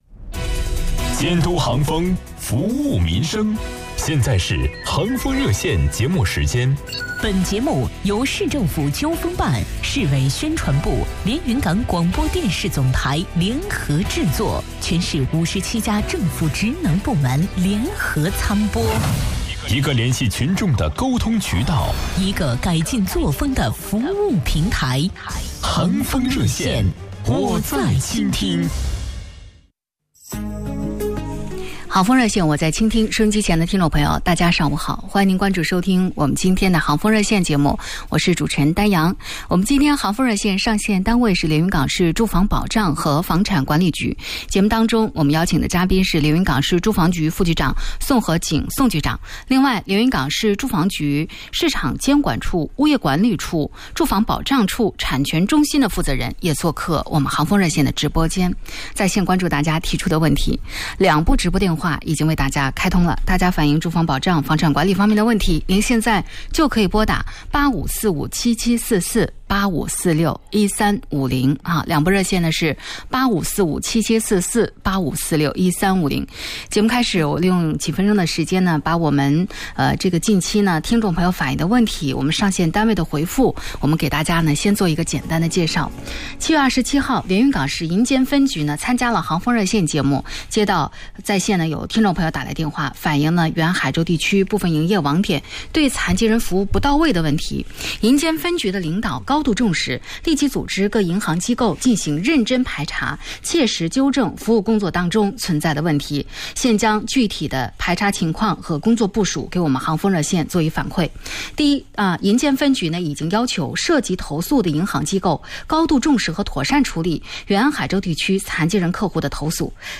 特邀嘉宾 副局长 宋和景